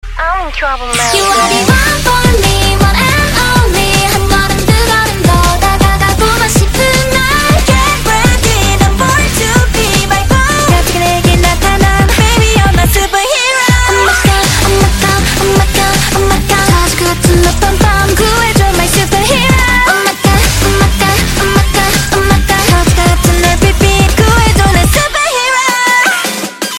Kpop Songs